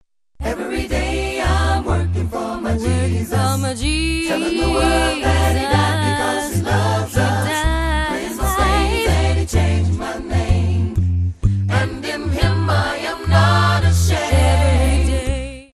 Your source for the best in A'cappella Christian Vusic ®
a cappella gospel songstress